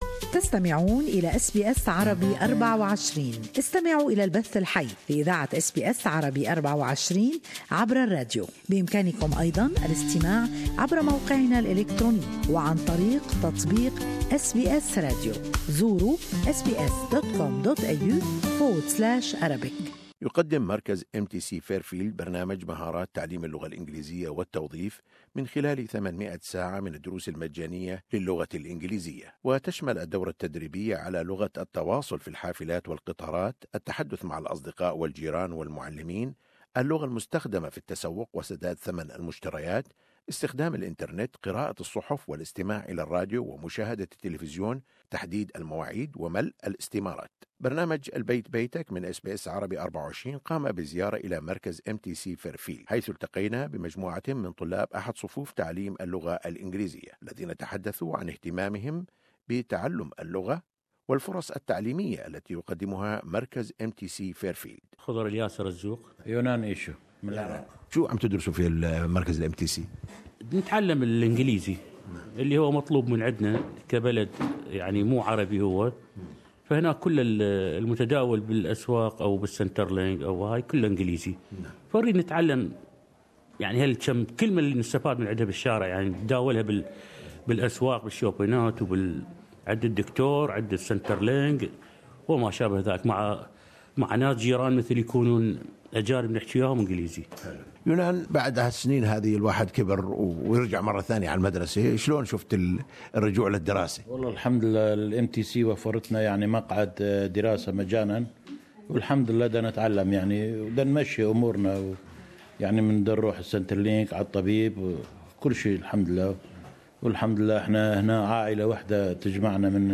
interviewed a group of students from an English language teaching class, who spoke of their interest in learning English and the educational opportunities offered by the MTC Fairfield Center.